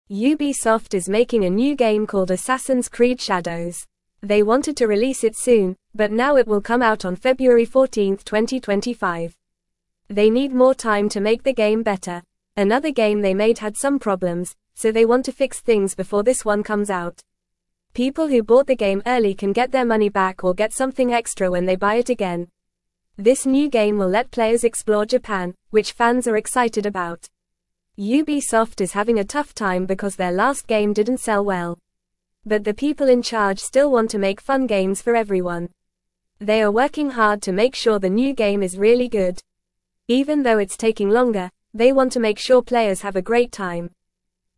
Fast
English-Newsroom-Beginner-FAST-Reading-Ubisoft-delays-new-game-to-make-it-better.mp3